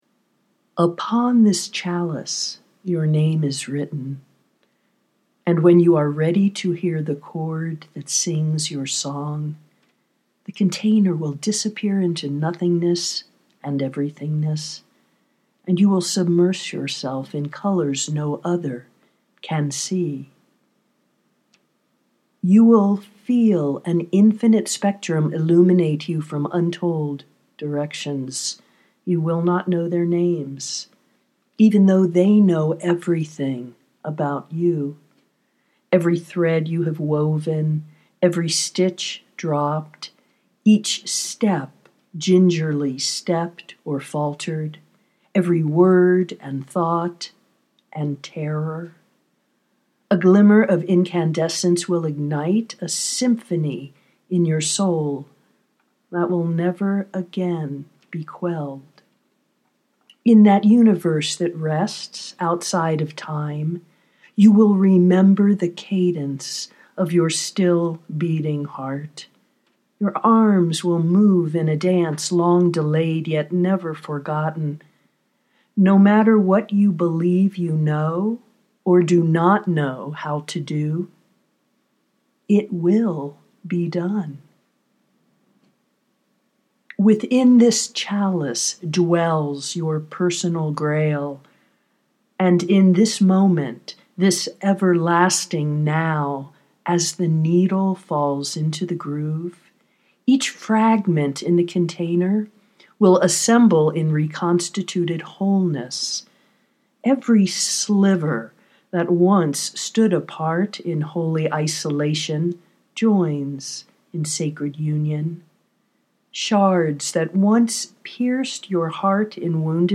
journey for the fearless (audio poetry 4:44)